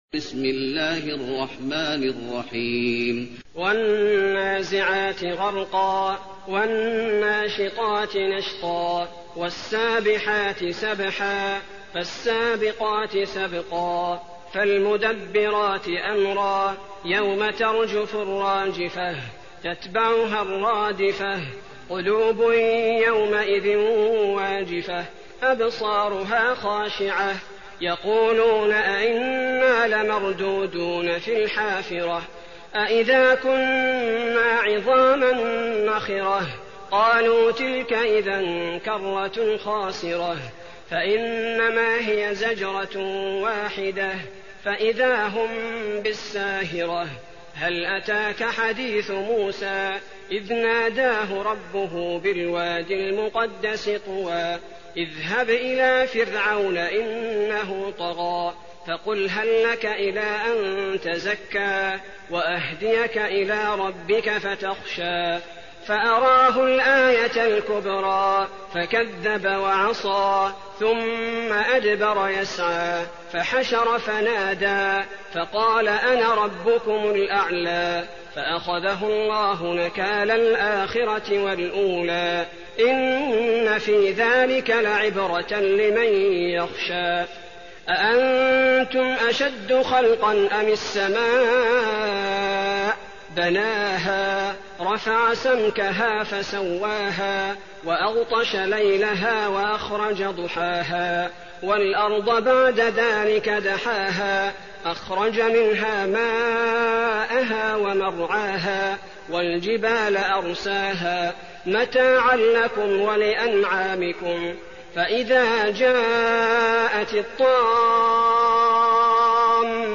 المكان: المسجد النبوي النازعات